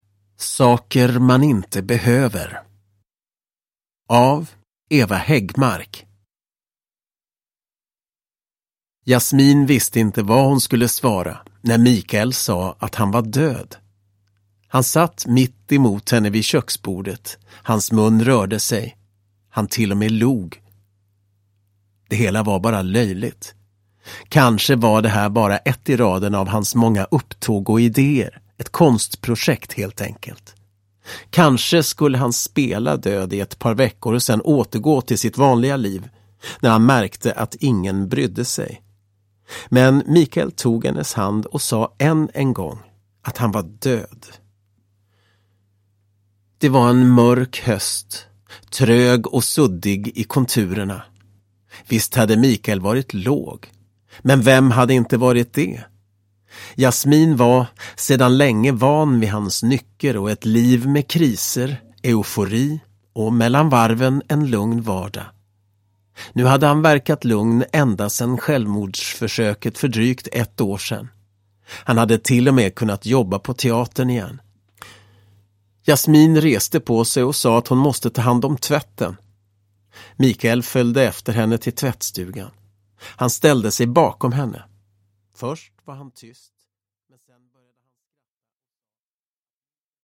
Saker man inte behöver: Mörkret utanför del 3 – Ljudbok – Laddas ner